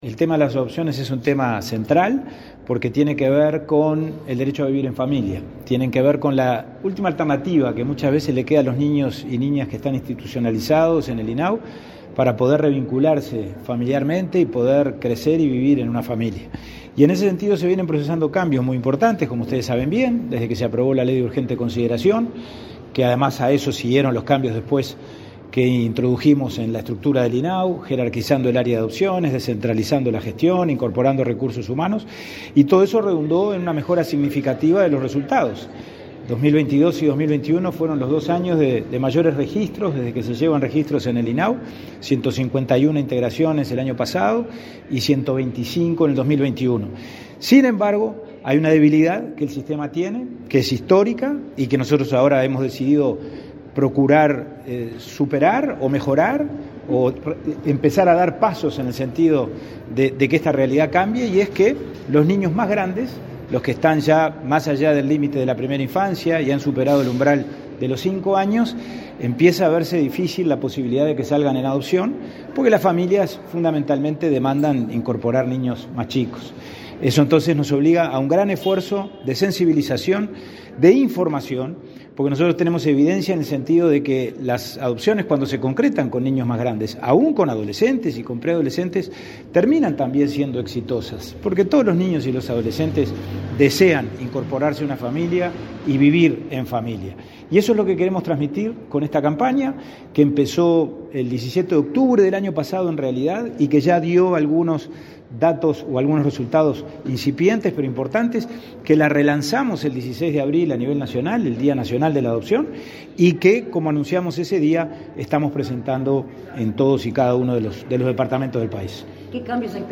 Declaraciones a la prensa del presidente del INAU, Pablo Abdala